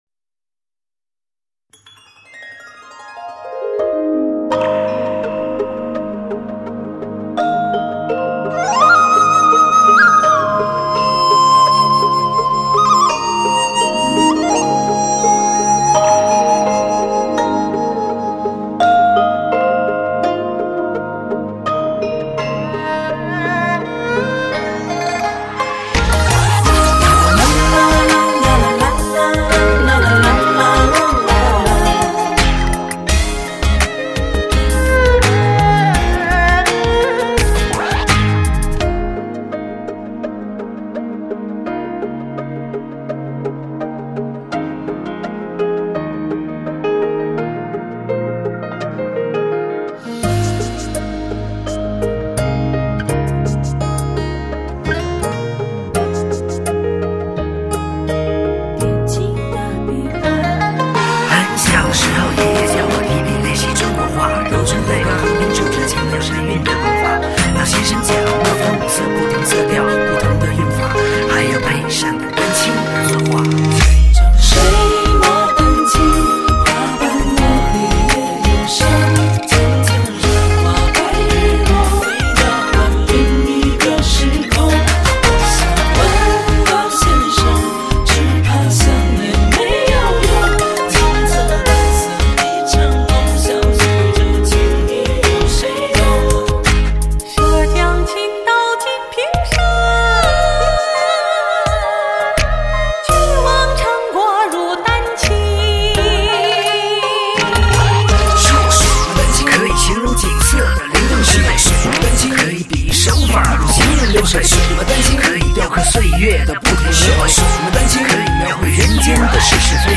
传承中华传统文化，首个将中国戏曲与R&B、蓝调等多种音乐元素完美结合，